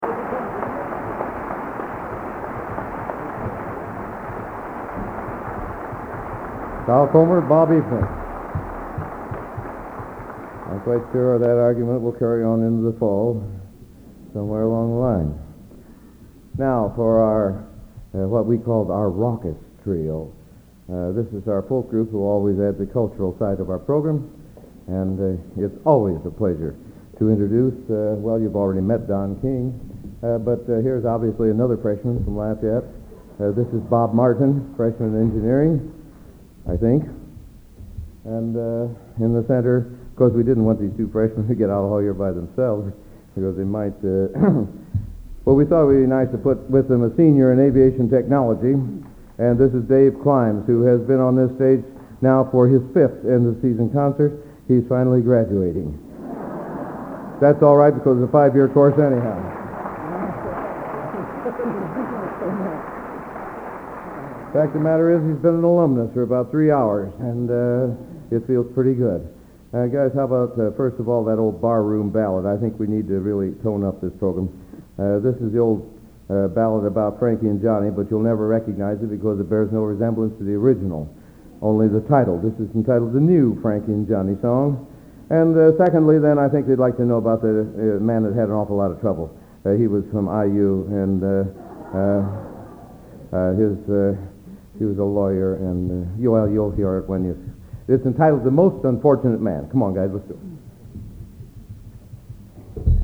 Collection: End of Season, 1979
Genre: | Type: Director intros, emceeing